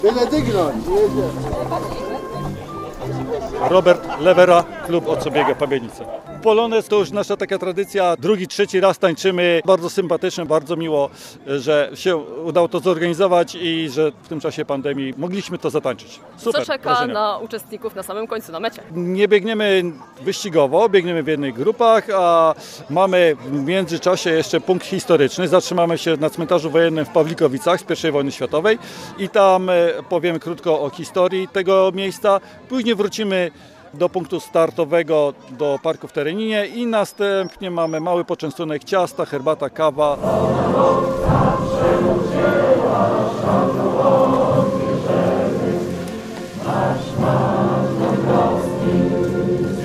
Nazwa Plik Autor Bieg z okazji Święta Niepodległości w Pabianicach audio (m4a) audio (oga) Po hymnie uczestnicy ruszyli do biegu na dystansie jedenastu kilometrów.